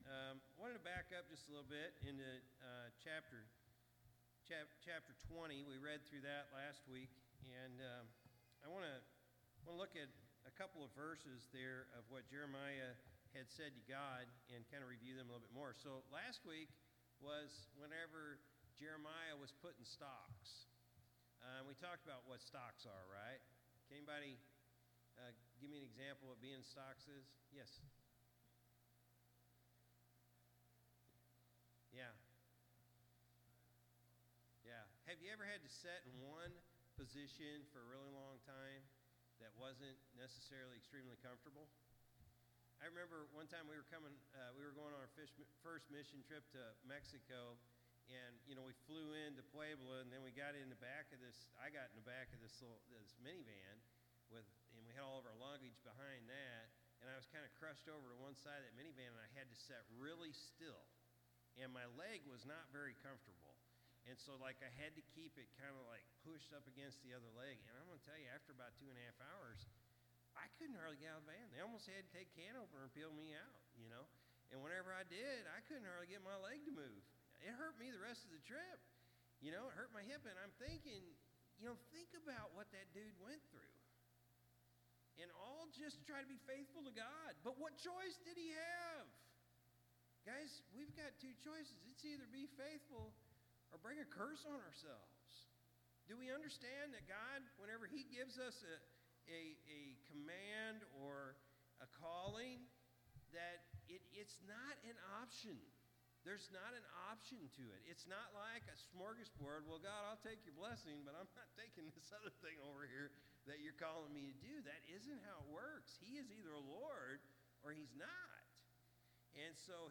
September-8-2024-Evening-Service.mp3